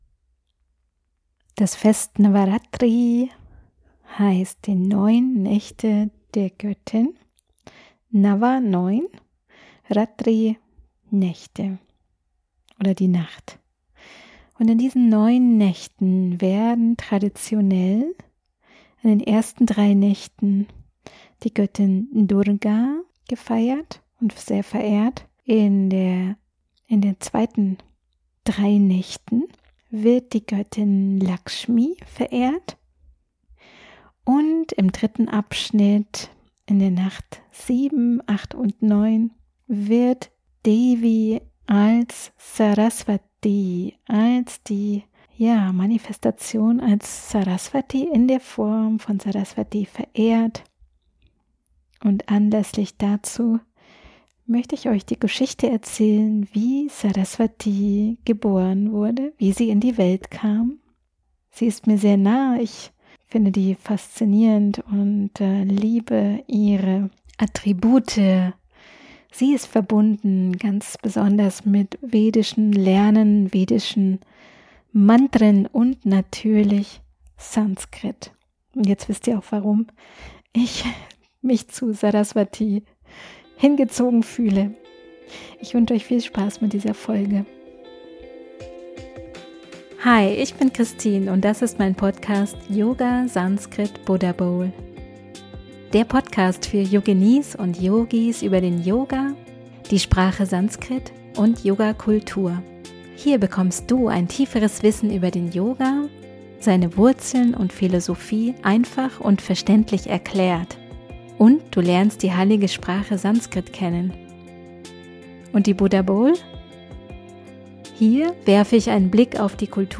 28 I Sarasvatī - wie sie "geboren" wurde (Live STORY) ~ YOGA SANSKRIT BUDDHABOWL Podcast